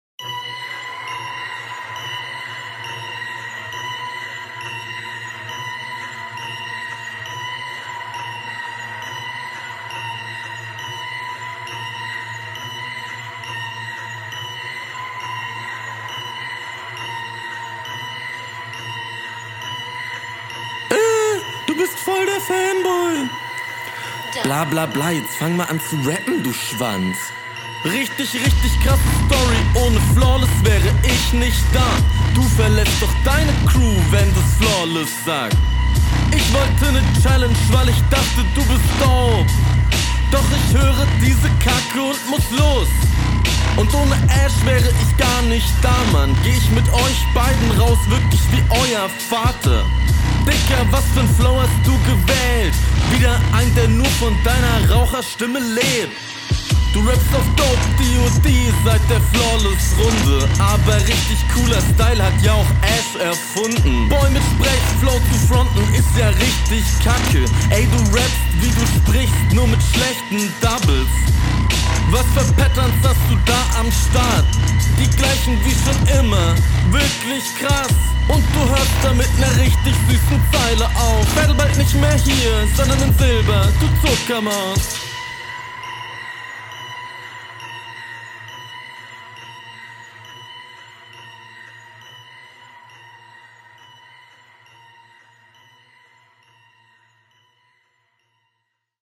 Manchmal Flow nicht ganz rund.